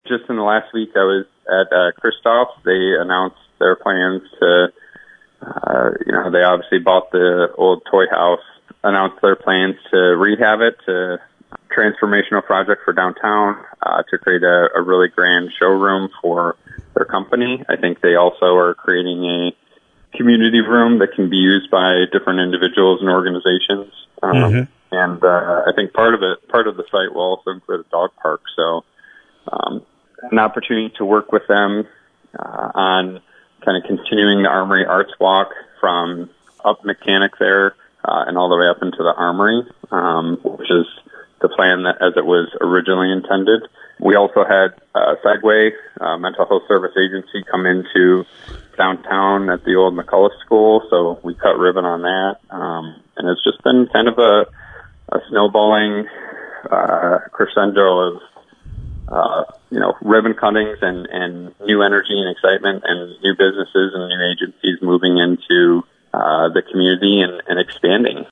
Wednesday morning on WKHM Jackson Mayor Derek Dobies talked about some new lighting for Cooper Street that will be mostly paid for by property owners through an assessment…